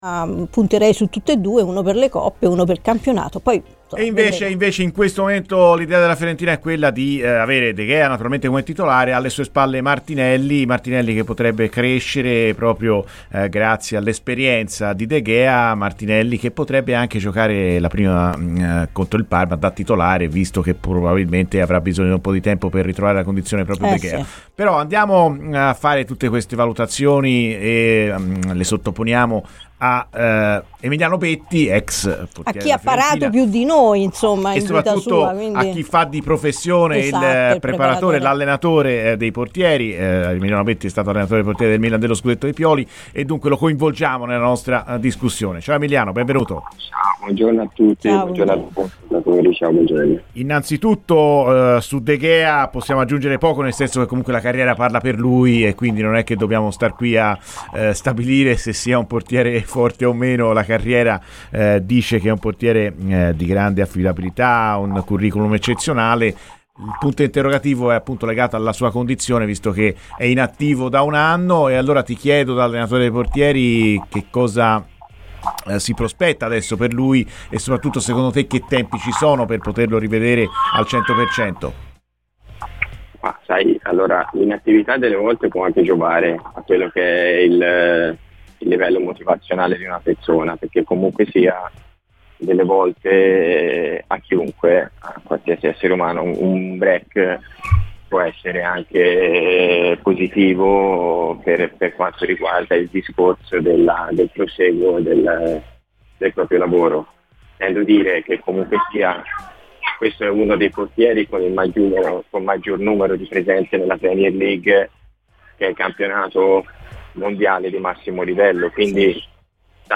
è intervenuto ai microfoni di Radio FirenzeViola